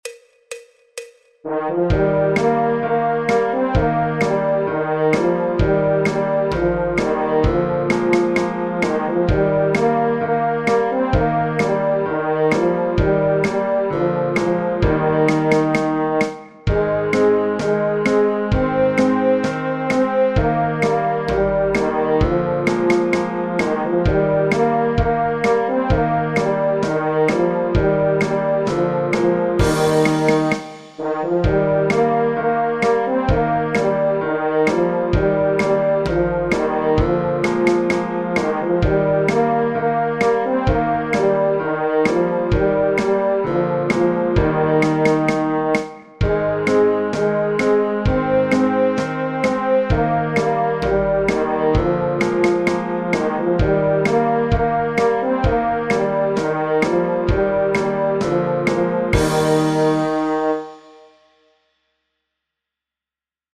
El MIDI tiene la base instrumental de acompañamiento.
Trompa / Corno Francés
Do Mayor
Folk, Popular/Tradicional